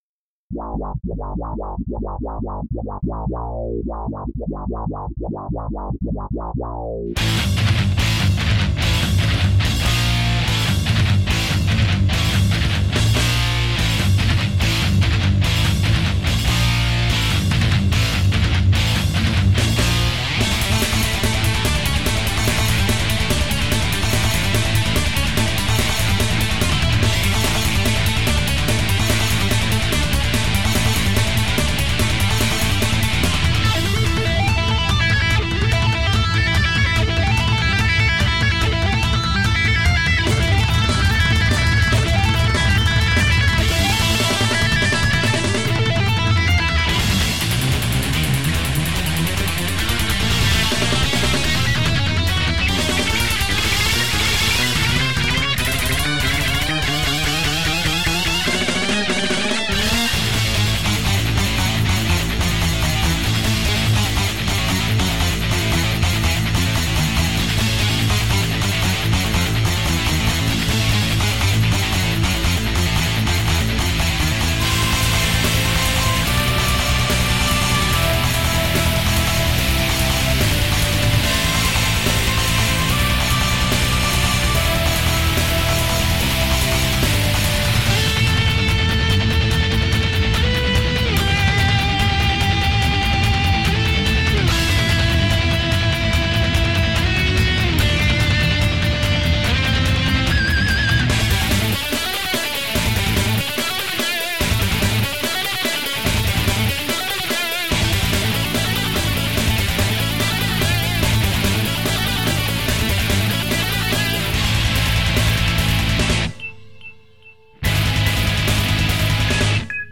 While my guitar gently shreds.
Instrumental progressive metal with a crossover twist!